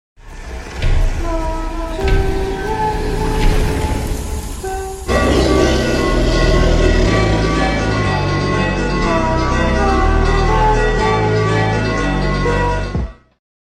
★ His Roars Sound Amazing! sound effects free download